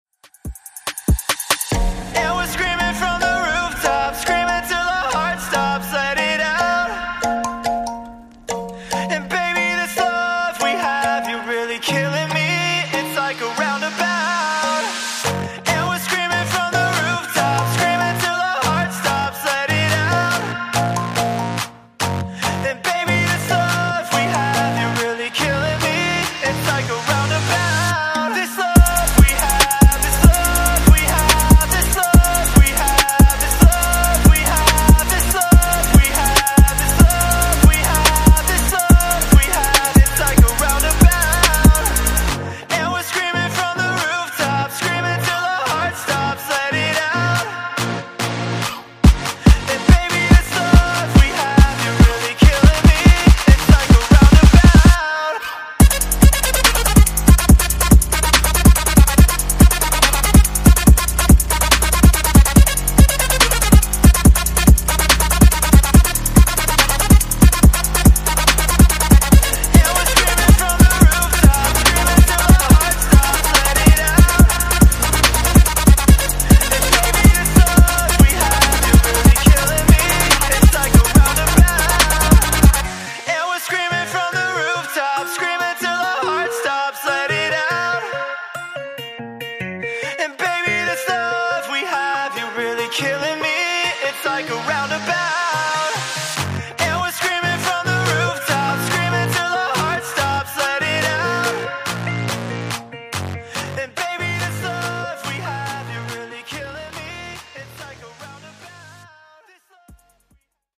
Genres: RE-DRUM , REGGAETON
Clean BPM: 98 Time